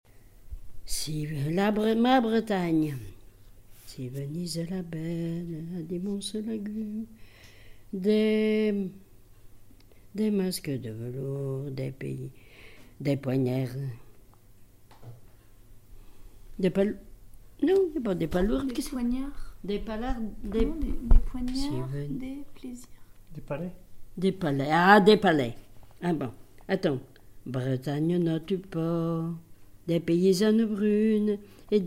Mémoires et Patrimoines vivants - RaddO est une base de données d'archives iconographiques et sonores.
chanson extraite d'un recueil manuscrit
Genre laisse
Pièce musicale inédite